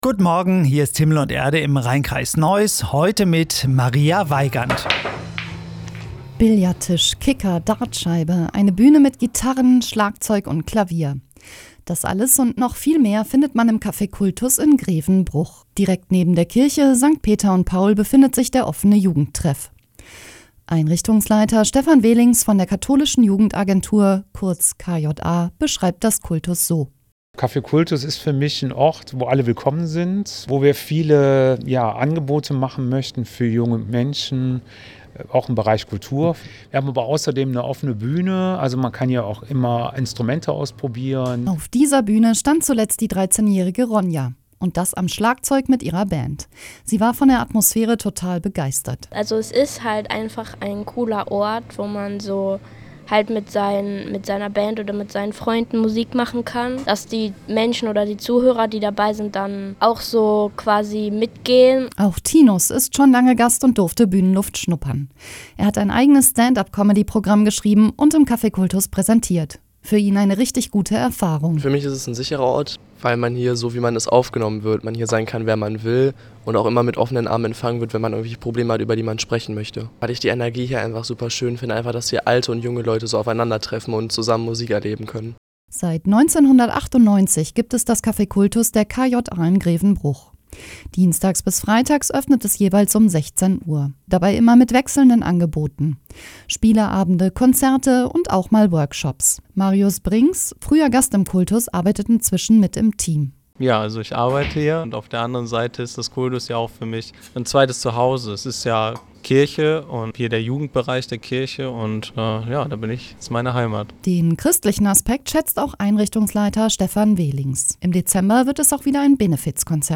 Radiobeitrag zum Nachhören.